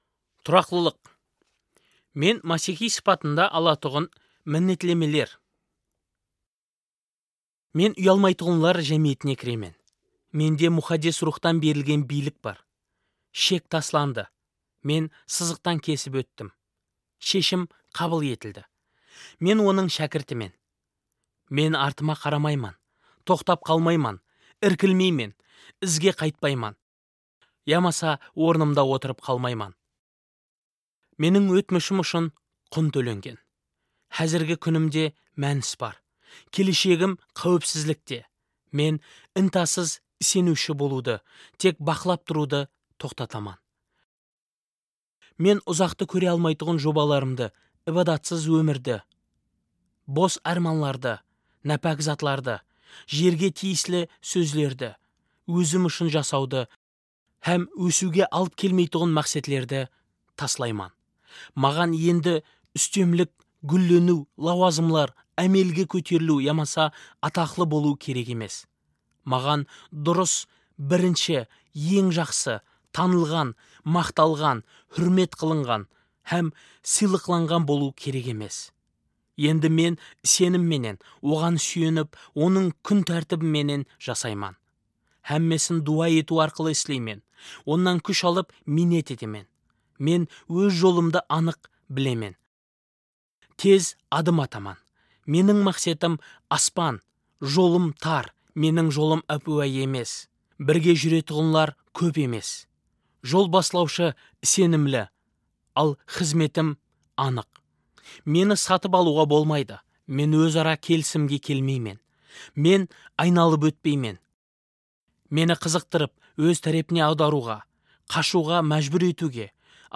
Африкалы жас пастор